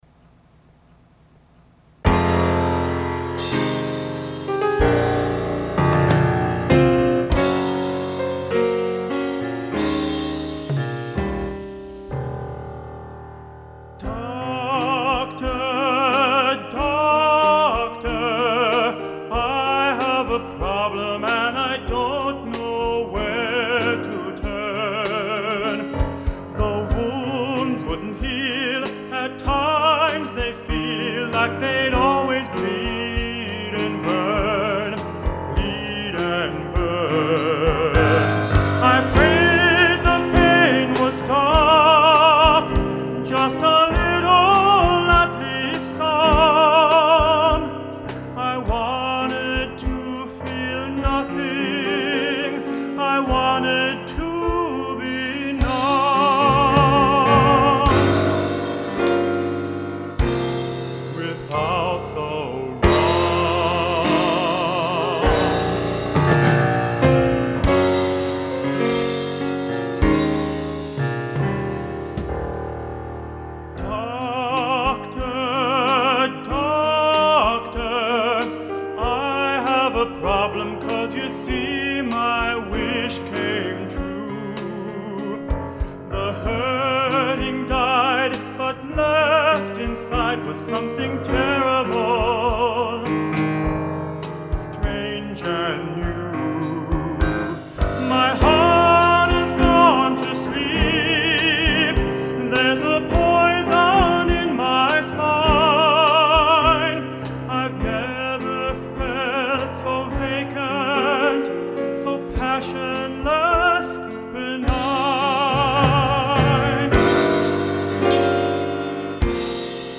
Doctor, Doctor Composer and Piano
Vocals
Drums
recorded in Lübeck Germany (1991-1992)